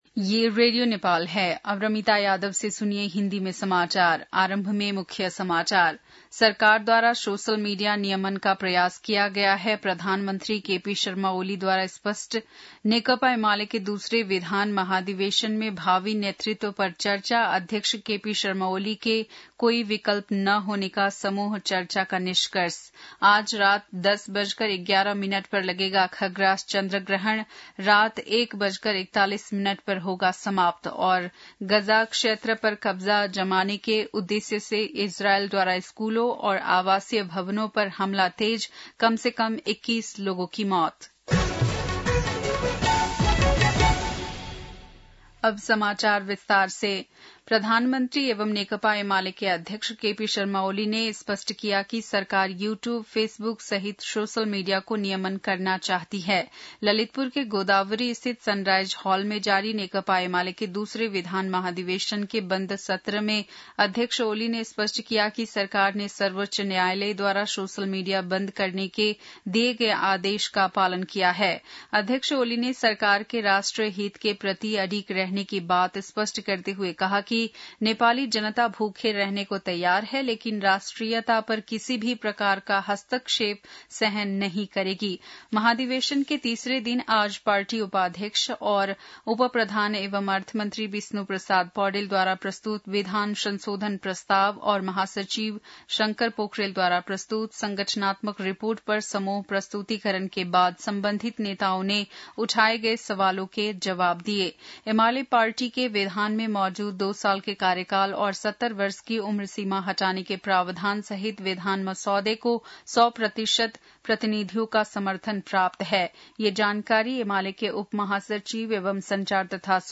बेलुकी १० बजेको हिन्दी समाचार : २३ भदौ , २०८२
10-pm-hindii-news-.mp3